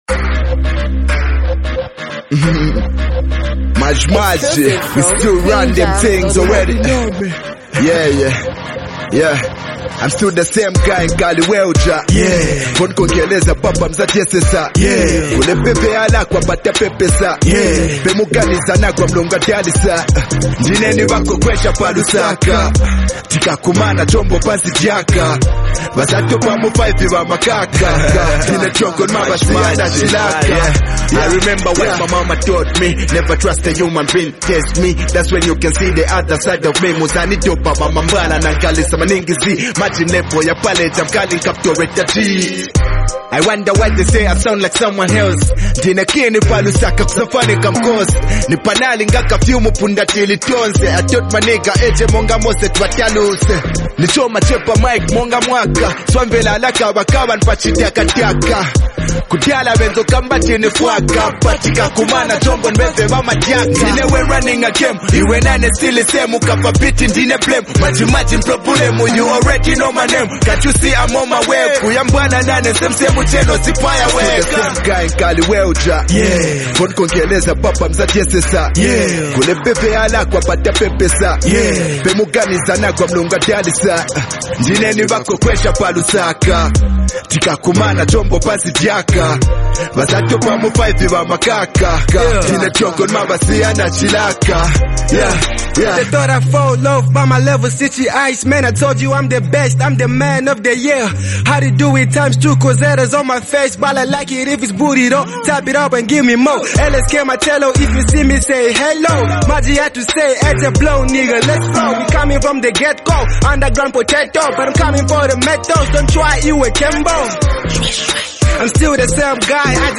vibrant urban anthem